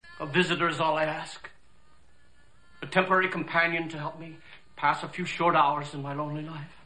hackman.mp3